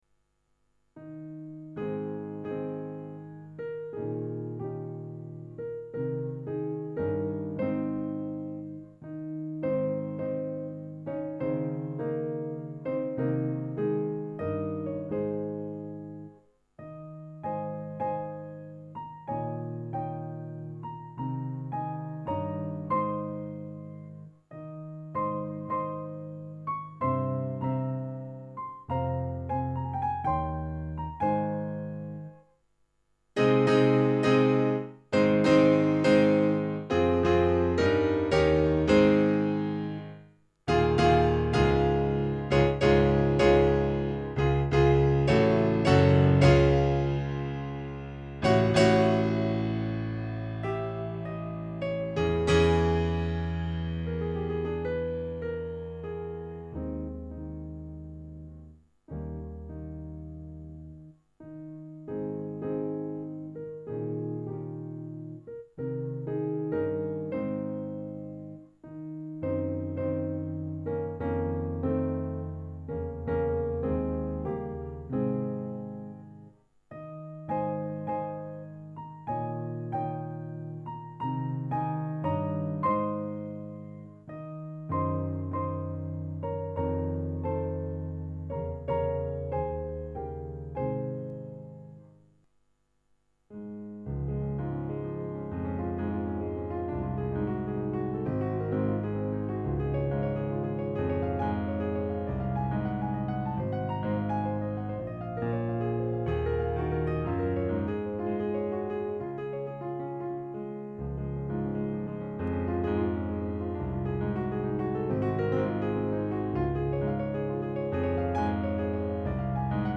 F_-Schubert---Impromptu-2---Rock-Grand-_C.mp3